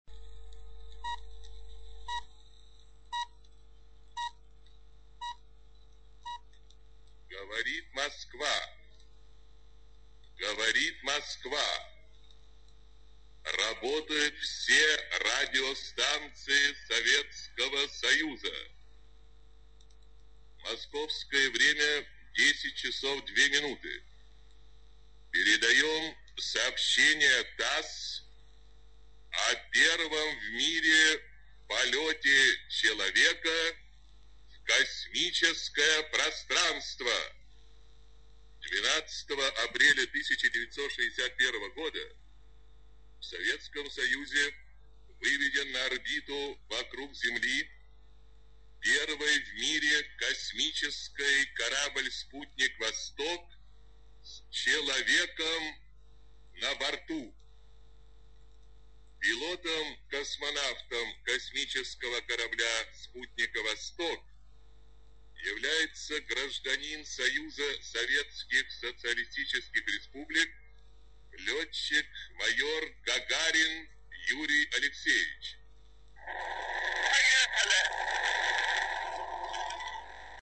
Сообщение ТАСС о первом в мире полёте человека в космос. Читает диктор Ю.Б. Левитан (фрагмент)